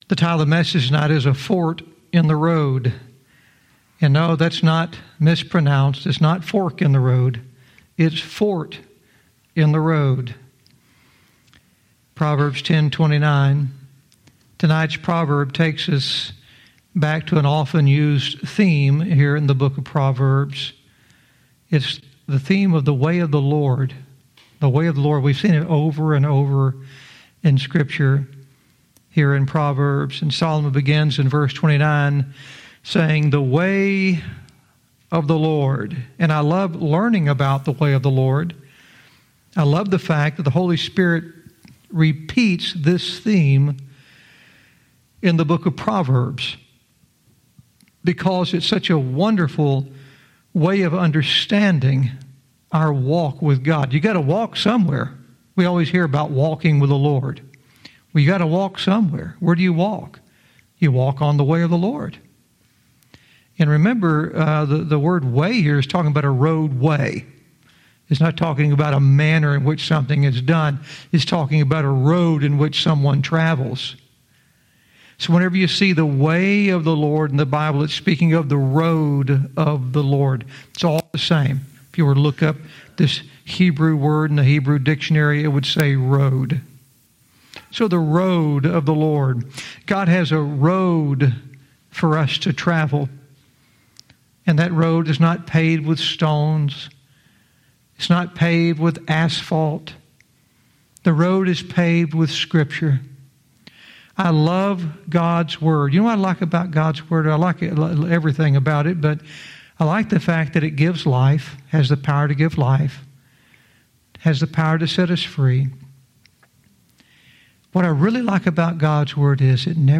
Verse by verse teaching - Proverbs 10:29 "A Fort in the Road"